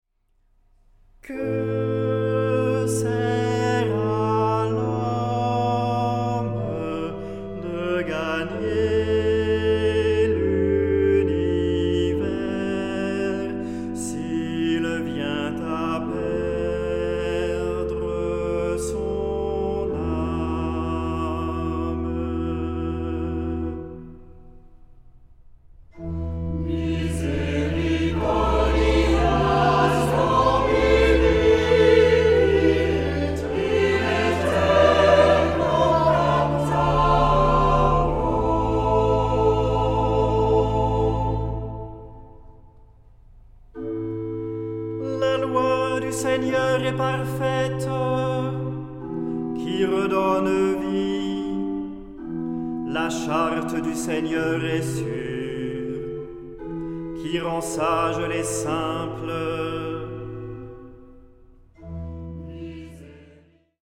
Género/Estilo/Forma: Sagrado ; tropario ; Salmo
Carácter de la pieza : con recogimiento
Tipo de formación coral: SATB  (4 voces Coro mixto )
Instrumentos: Organo (1) ; Instrumento melódico (1)
Tonalidad : la mayor ; la menor